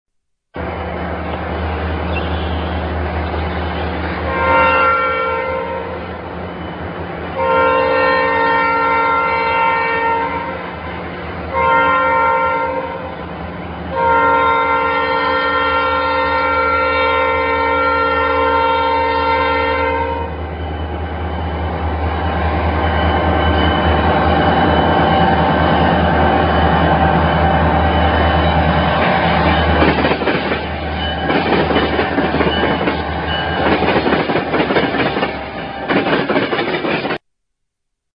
AudioOne thing you will never hear again is a train whistling for West Jefferson Blvd.